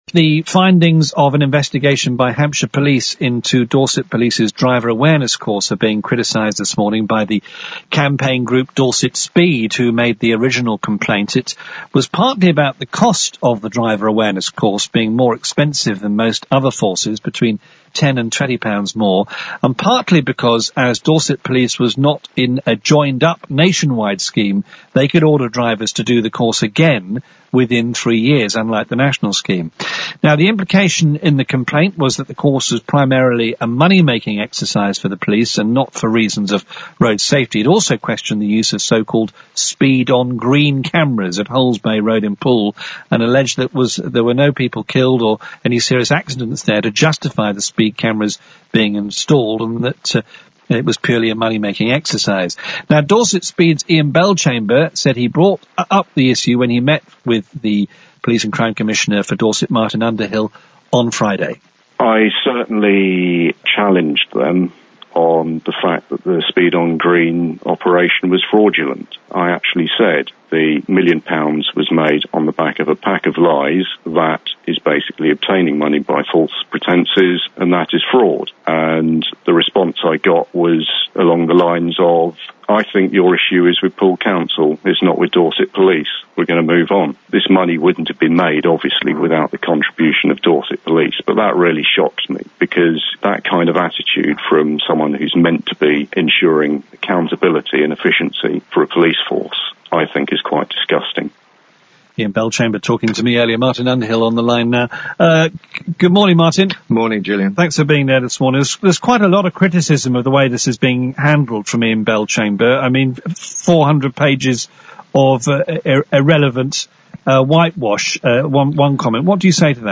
Second extract - Martyn Underhill